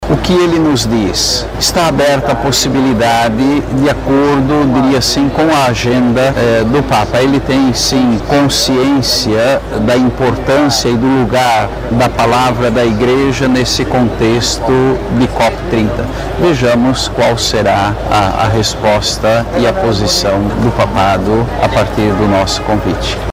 Sonora-2-–-Dom-Jaime-Spengler-.mp3